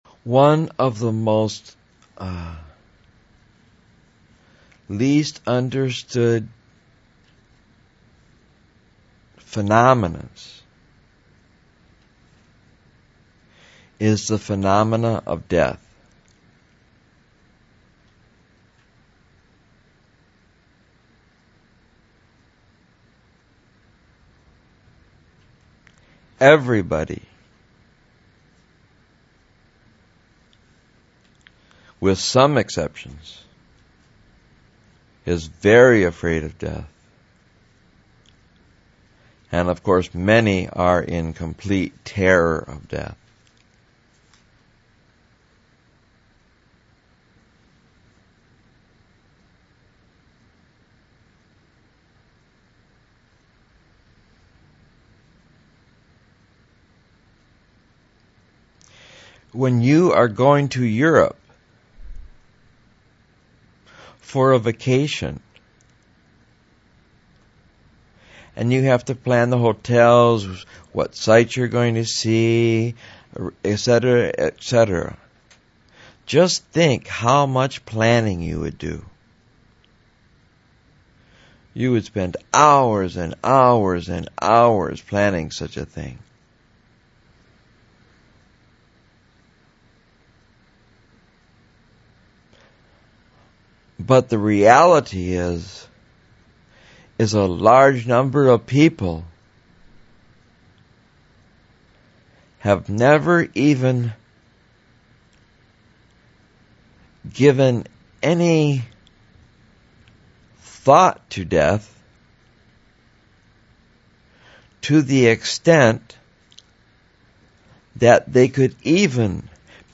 Once the talk is complete and for the remaining of the time you can meditate to deep church music.